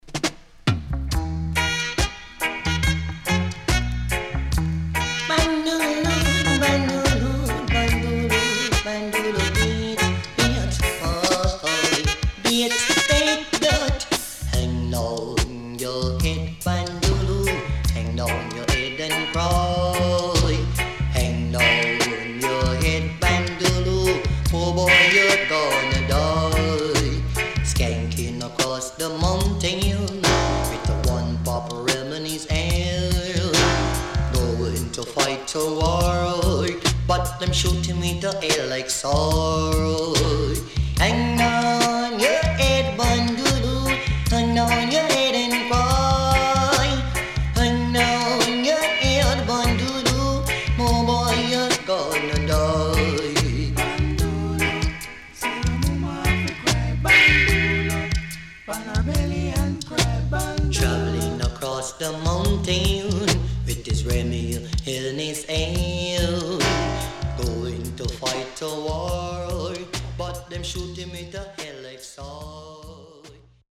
HOME > DISCO45 [DANCEHALL]  >  EARLY 80’s
SIDE A:所々チリノイズがあり、少しプチノイズ入ります。